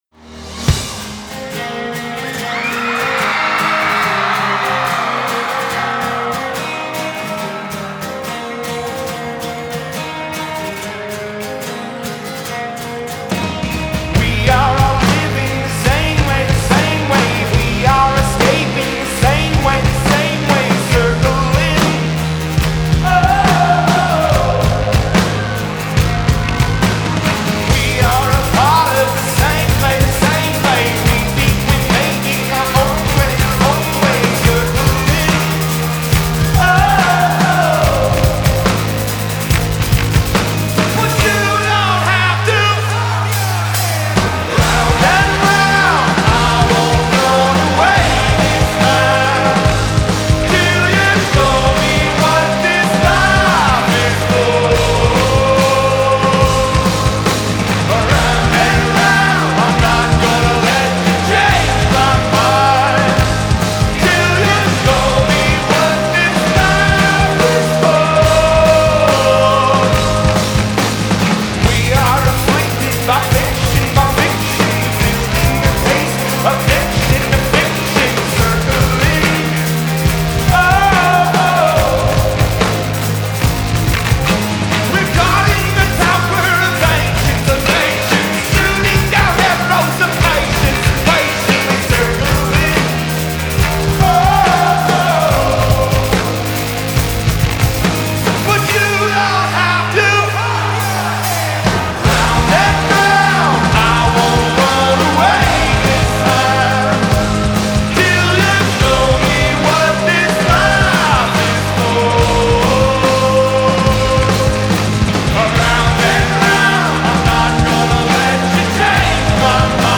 Genre : Alternative & Indie
Live From Red Rocks